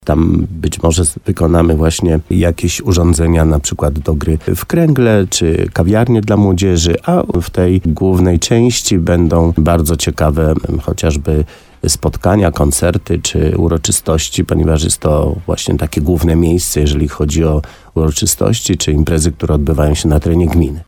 Co w niej powstanie zdradza Leszek Skowron wójt gminy Korzenna